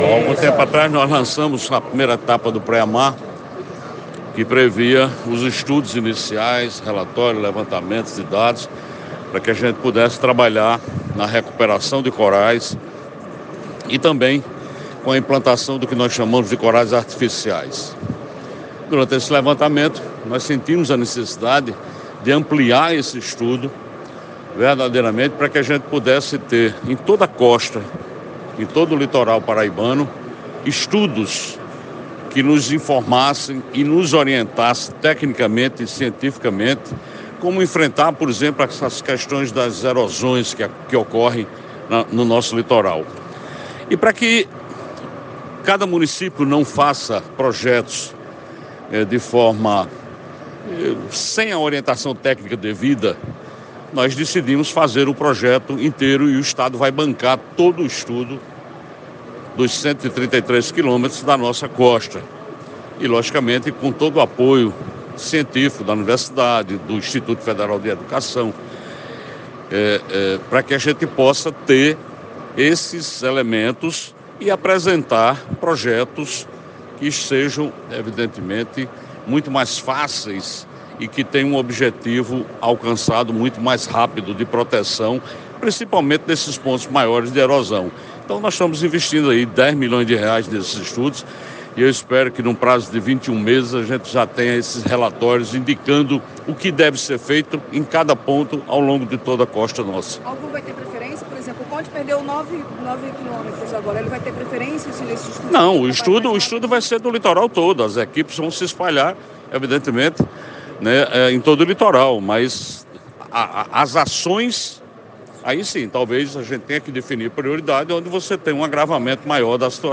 Em entrevista à imprensa paraibana, João Azevêdo deu mais detalhes das ações do programa que visa como enfrentar tais fenômenos.